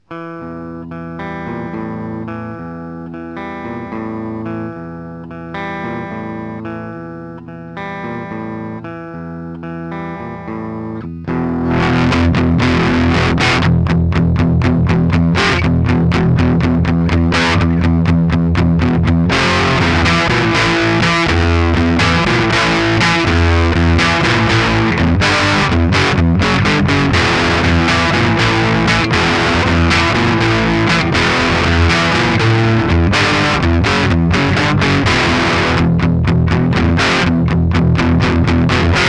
quelques samples pour te faire une idée (sortie line avec un cut des aigus pour simuler rapidement un cab)
fuzz_equivHP15.ogg